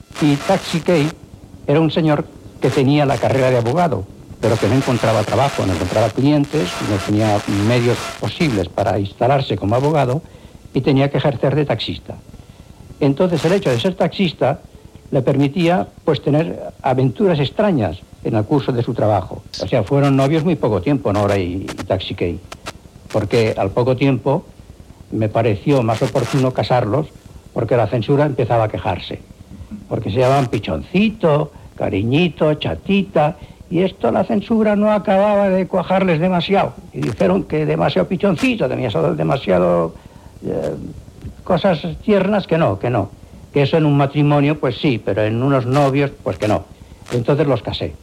Extret de Crònica Sentimental de Ràdio Barcelona emesa el dia 15 d'octubre de 1994